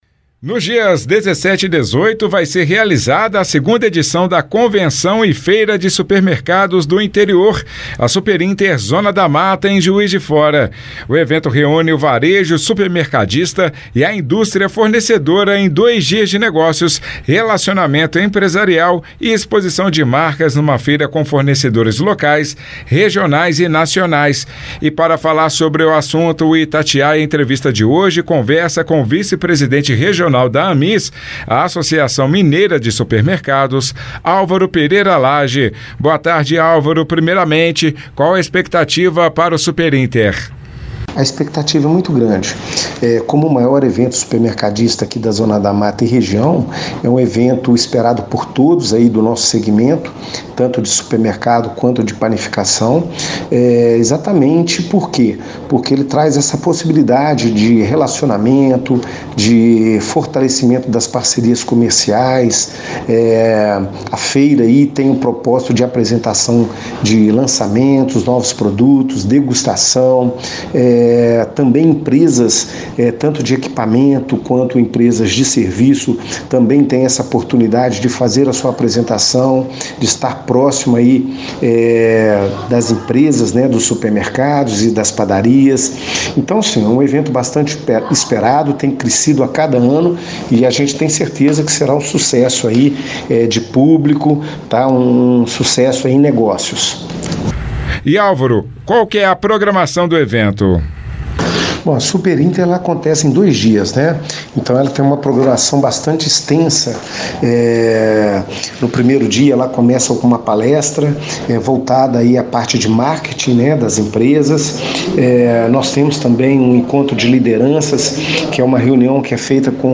falou sobre as expectativas e destaques da Superinter 2024 ao Itatiaia Entrevista.